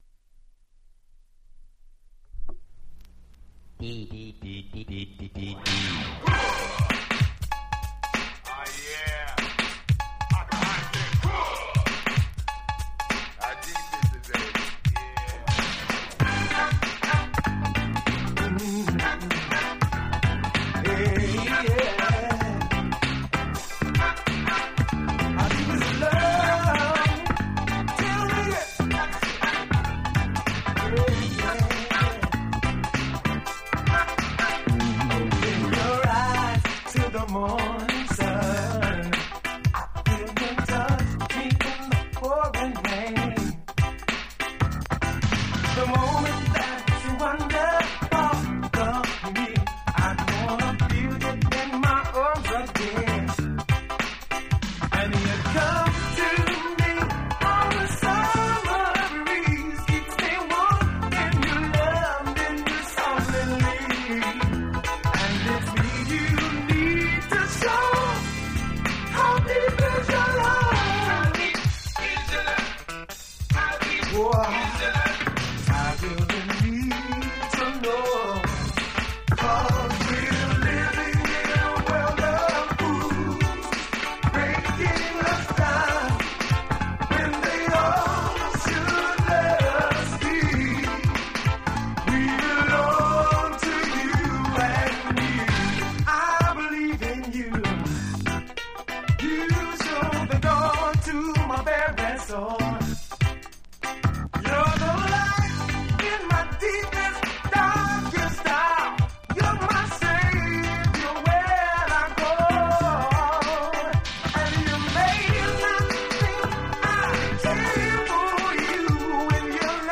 LISTEN : Compilation Digest
Media Condition (A/B) : VG *全体的にプチノイズ有、要試聴、ラベル書き込み